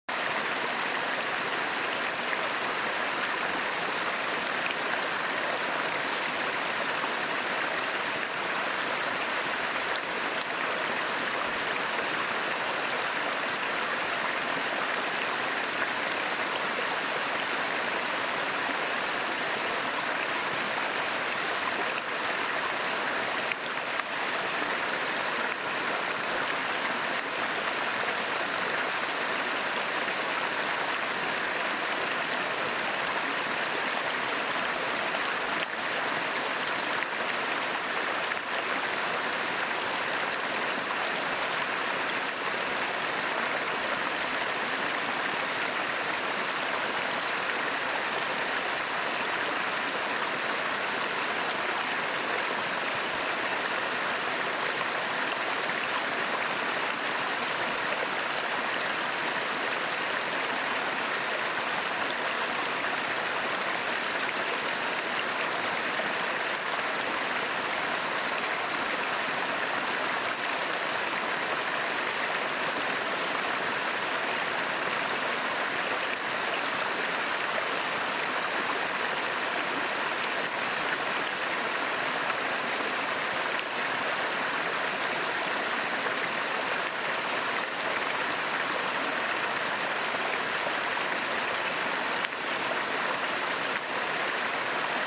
Lose yourself in the peaceful sound of a forest waterfall as light rain falls gently through the trees. The soft rush of water and the rhythm of the rain create the perfect natural lullaby. Ideal for sleep, meditation, or simply escaping the noise of the day.